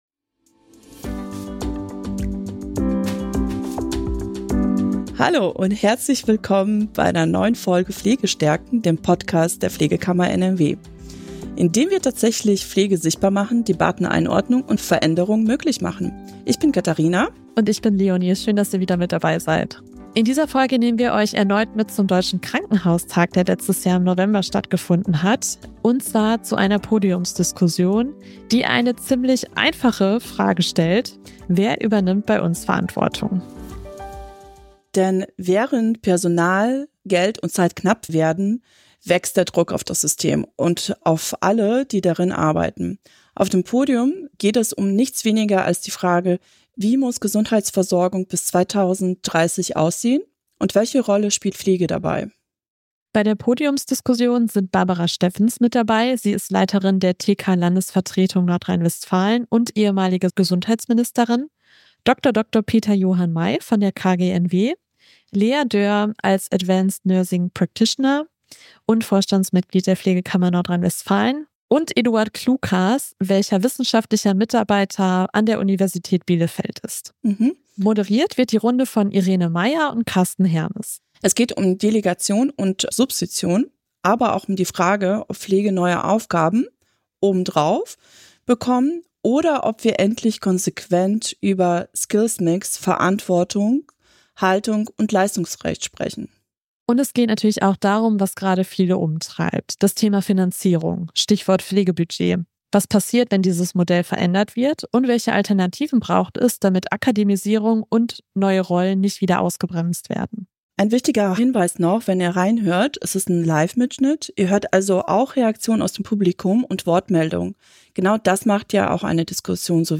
In dieser Folge vom Deutschen Krankenhaustag geht es deshalb um eine zentrale Frage: Wer übernimmt Verantwortung und wie muss Gesundheitsversorgung bis 2030 gestaltet werden, damit sie tragfähig bleibt? Im Mittelpunkt der Podiumsdiskussion stehen die großen Baustellen des Systems: Delegation und Substitution, neue Aufgabenverteilung, Finanzierung, Akademisierung und die Rolle der Pflege in einer zukunftsfähigen Versorgung. Diskutiert wird, warum es nicht reicht, Pflege einfach weitere Aufgaben zu übertragen, solange rechtliche Grundlagen, Ressourcen, tarifliche Perspektiven und klare Zuständigkeiten fehlen.